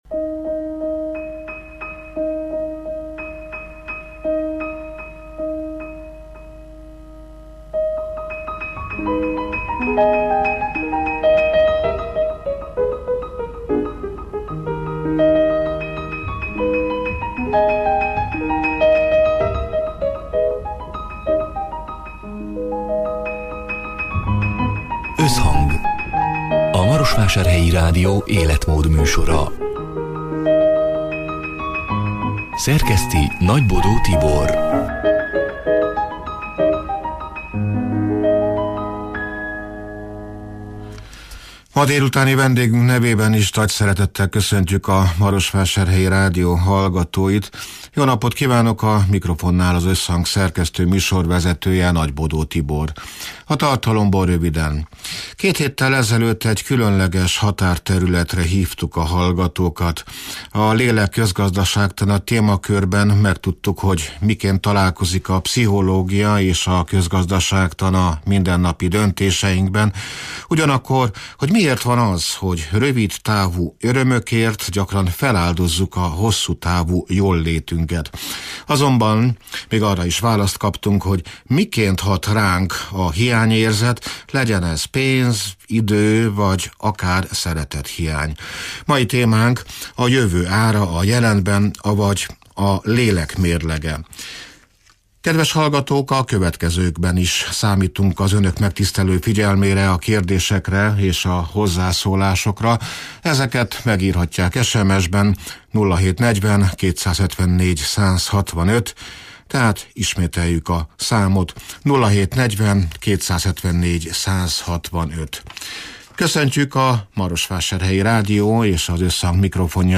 (elhangzott: 2026. február 18-án, szerdán délután hat órától élőben)